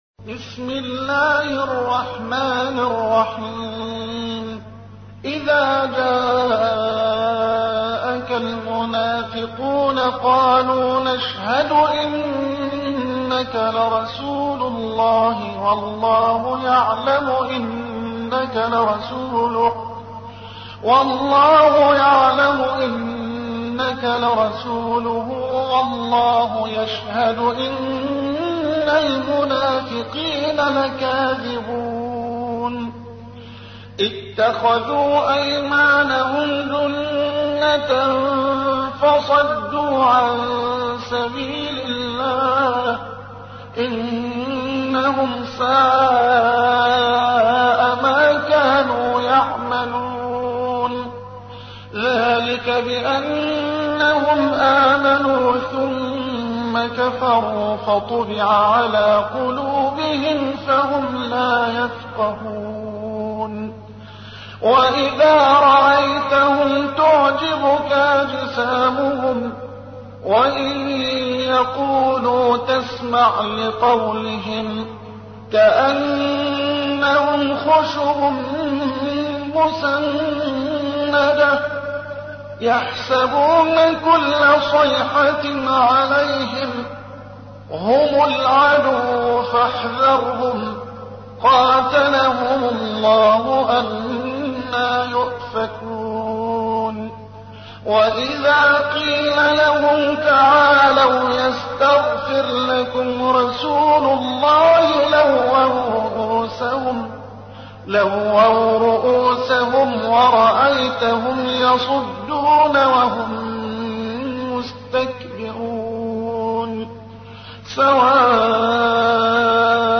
تحميل : 63. سورة المنافقون / القارئ محمد حسان / القرآن الكريم / موقع يا حسين